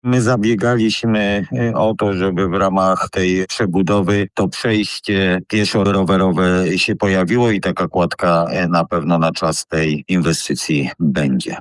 Posłuchaj TUTAJ wypowiedzi wójta Grzegorza Bobonia.